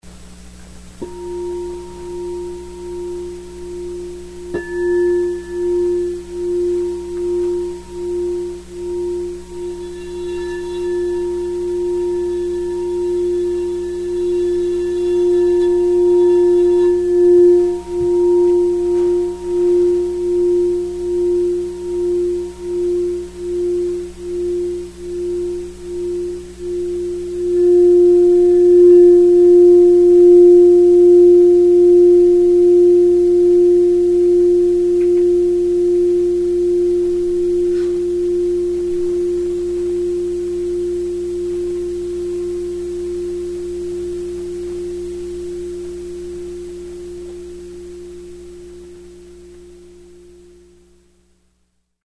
Singing Bowls
The internal spiral structure and the external rounded form of Crystal Singing Bowls produce a non linear, multidirectional Sound.
This process makes the bowls strong and incredibly pure in tone.
Son_Chakra_F_No.mp3